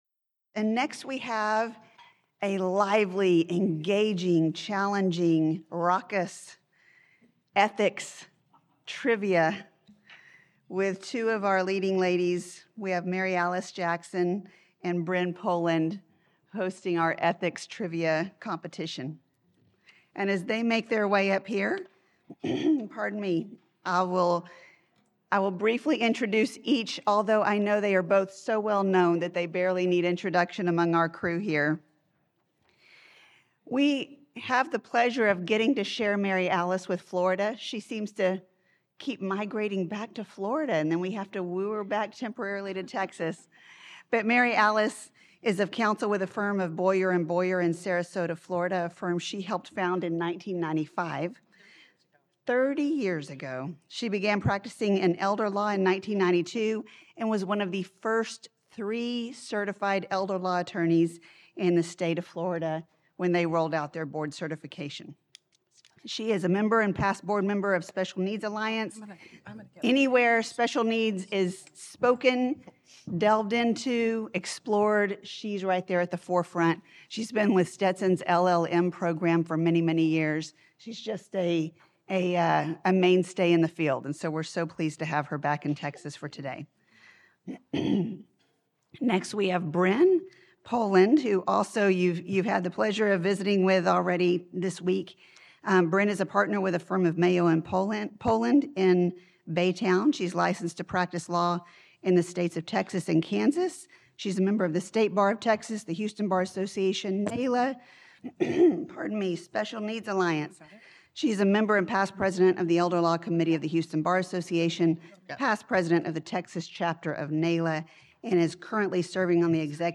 Attorneys and trustees need to clearly understand their ethical obligations. Using the Jeopardy game show format, in this interactive session, the presenters cover various ethical issues encountered in a special needs practice, providing the answers while the audience provides the questions.